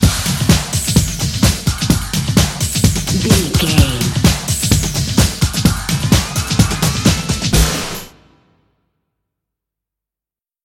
Aeolian/Minor
Fast
drum machine
synthesiser
electric piano
Eurodance